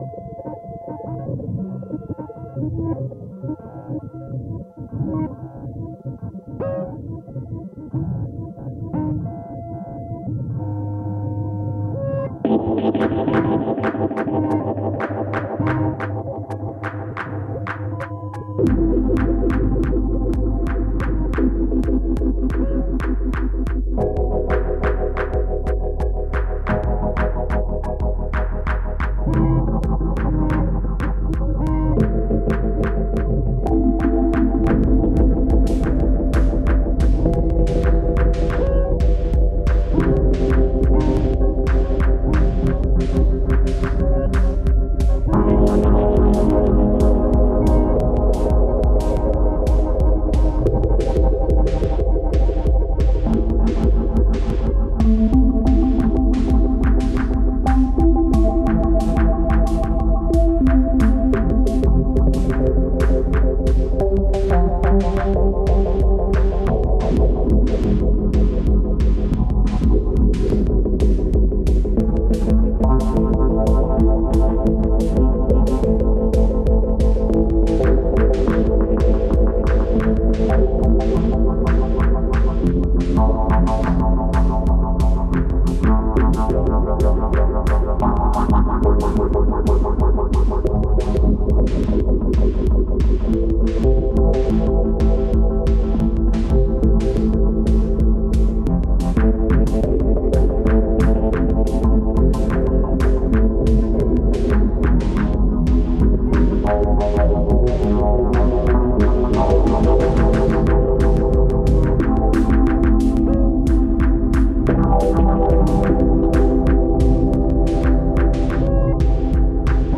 The electric piano only has drive, phaser, tremolo, reverb and two different delays (one on-board to the piano, and my RE-20 Space Echo). Everything gets a good dose of compression in the DAW.
It is both energetic and relaxing. The start reminds me a mad scientist’s lab, so I approve… not that I’m a mad scientist.